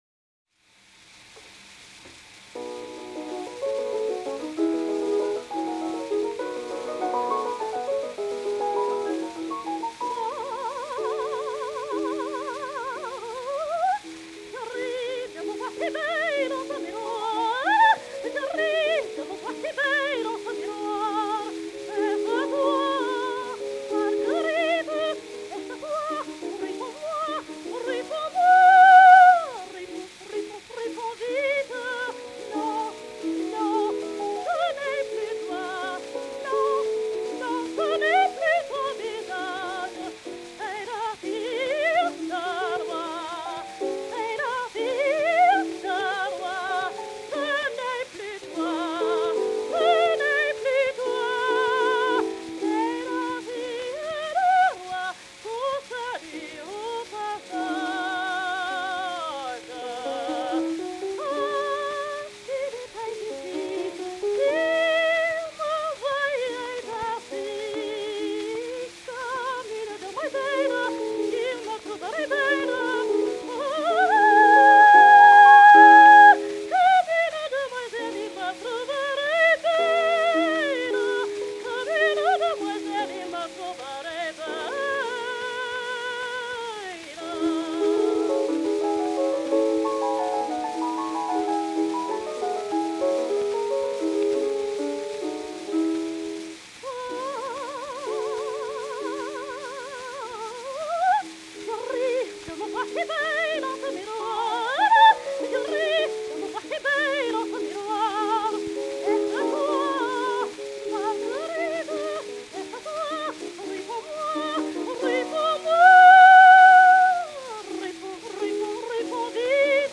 Australian soprano Dame Nellie Melba
Music by Charles Gounod and words by Jules Barbier and Michel Carré, accompanied by Landon Ronald, recorded by G&T 03016, September 1905
Presenters: Dame Nellie Melba